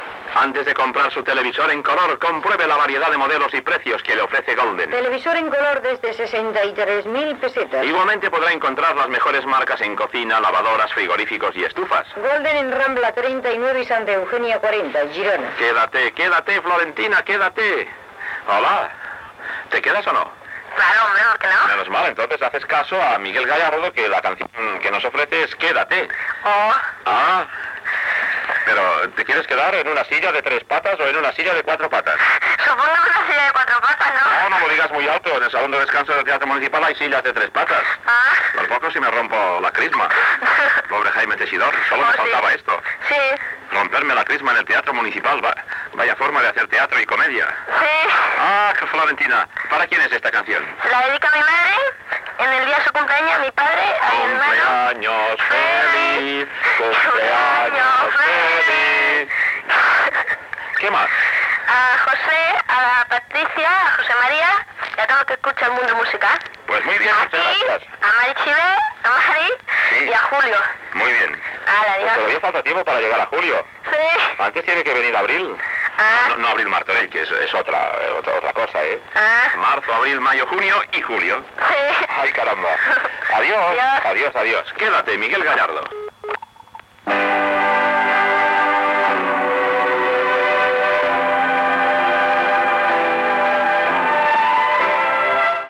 Publicitat i trucada per fer dedicatòries
Musical